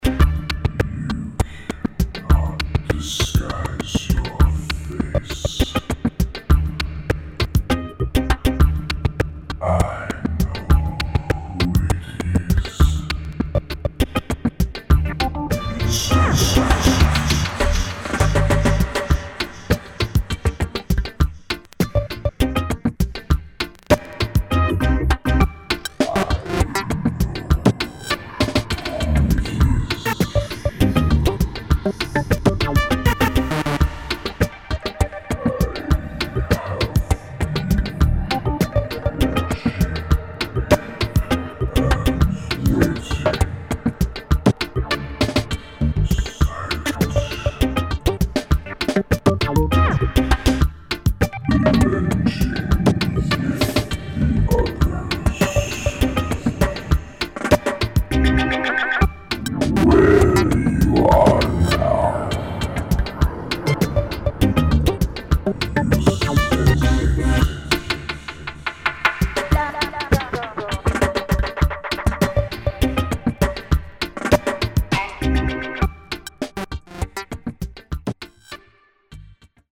[ EXPERIMENTAL / DOWNBEAT ]